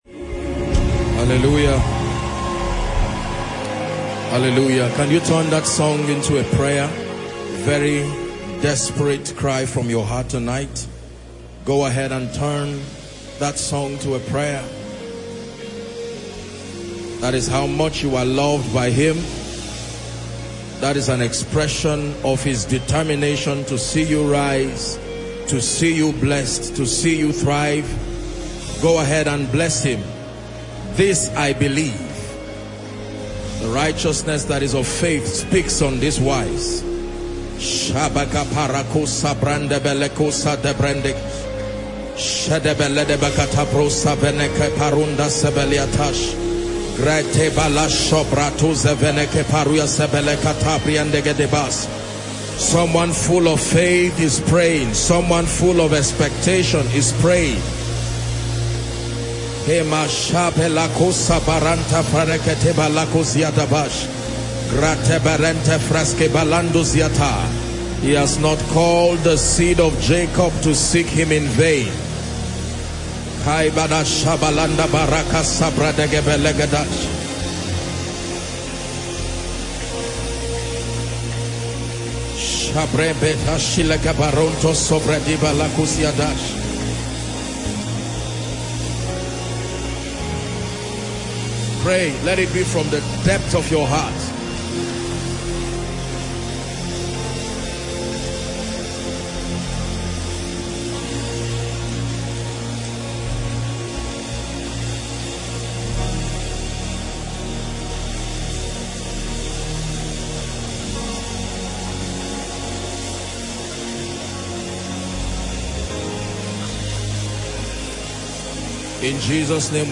The Miracle Service, held in January 2024, is a testament to the spiritual depth and enlightenment offered by Koinonia Abuja.